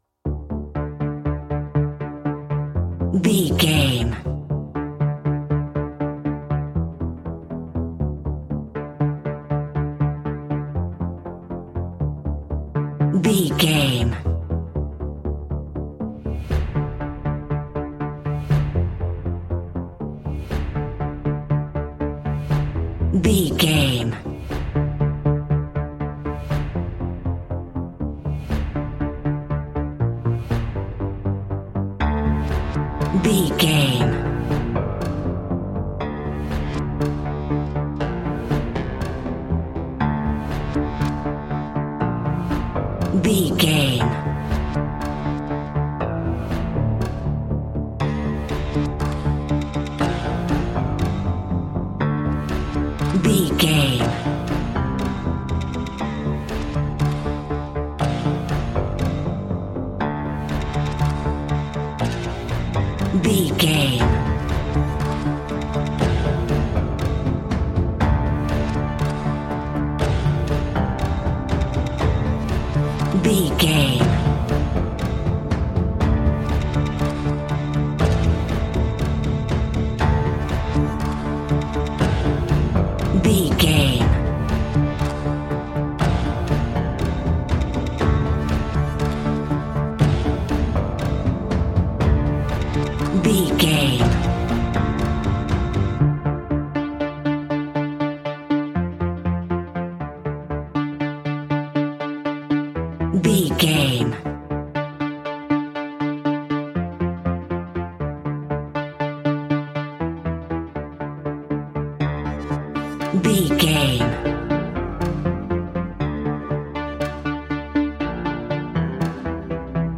Aeolian/Minor
D
ominous
dark
haunting
eerie
electric guitar
drums
synthesiser
horror music